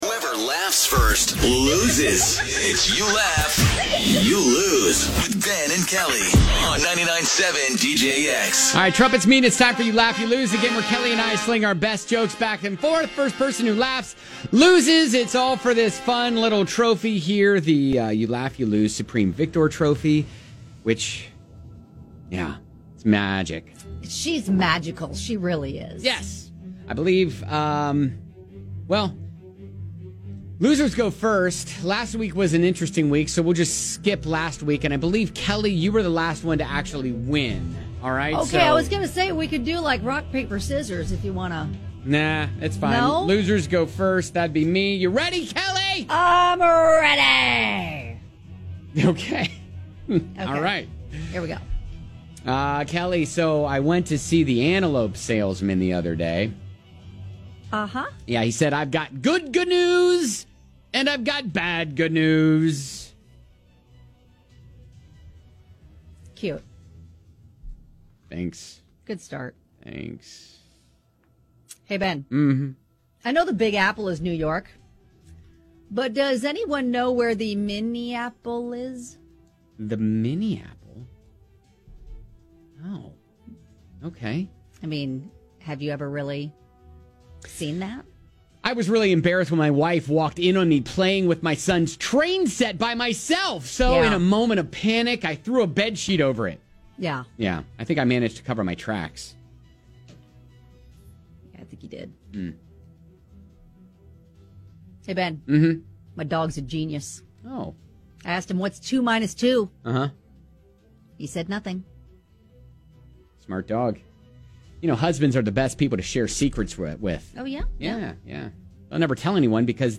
tell jokes and try not to laugh